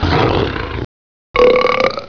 Majd pedig egy morgás és egy jóllakott böfögés.